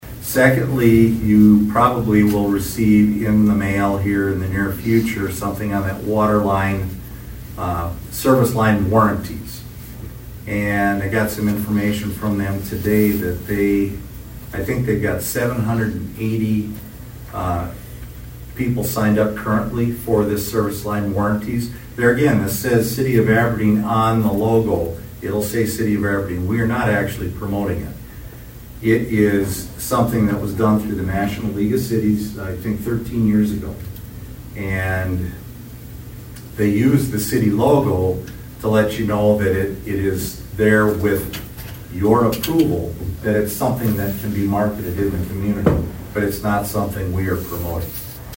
ABERDEEN, S.D.(HubCityRadio)- Aberdeen City Manager Robin Bobzien toward the end of the council meeting addressed a couple of water related topics during his City Manager Report.